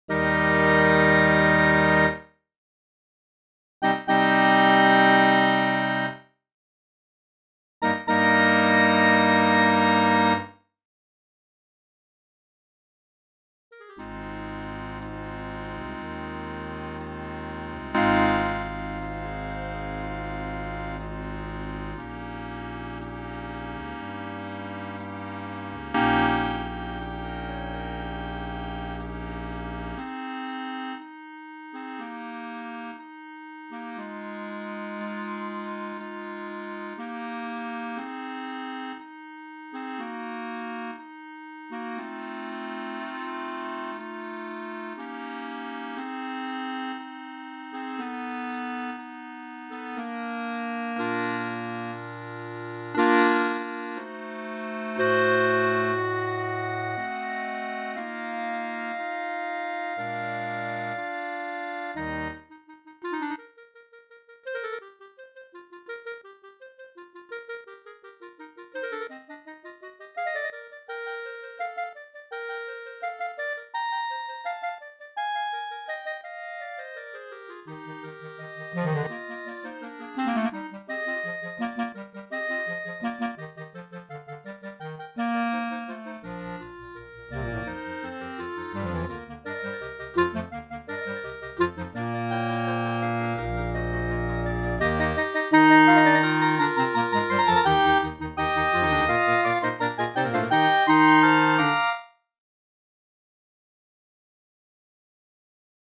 This lovely overture works very well for clarinet choir.
Tricky tuning in sustained chords.